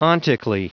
Prononciation du mot ontically en anglais (fichier audio)
Prononciation du mot : ontically